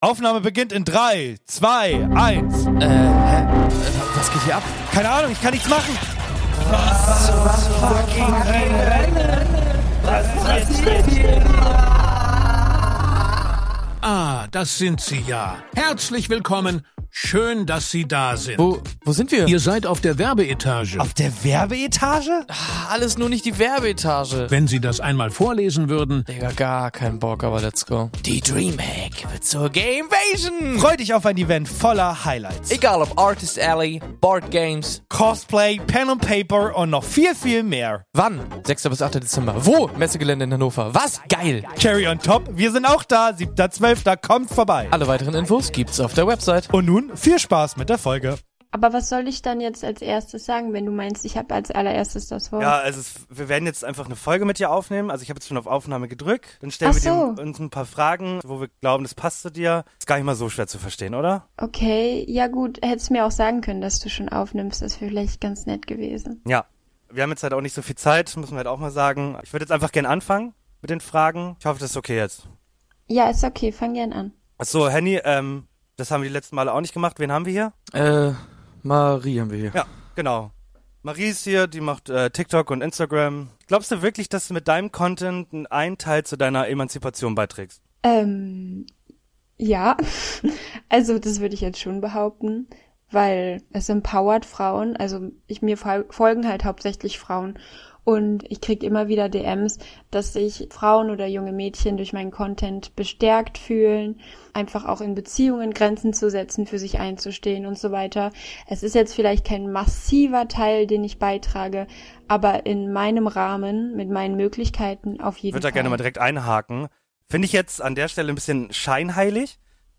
Zwei Jungs, ein Dreamteam!